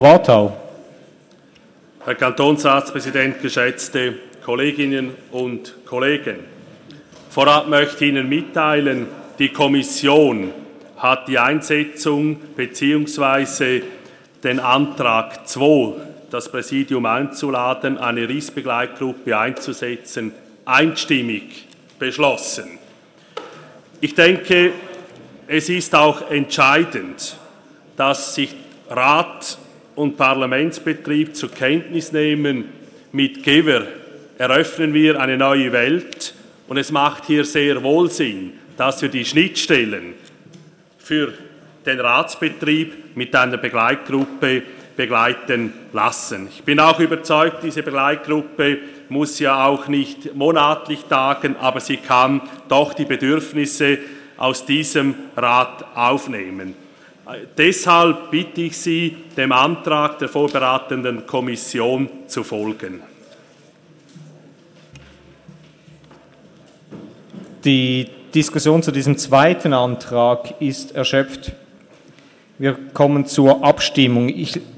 Session des Kantonsrates vom 12. und 13. Juni 2017
Kommissionspräsident: Dem Antrag der vorberatenden Kommission ist zuzustimmen.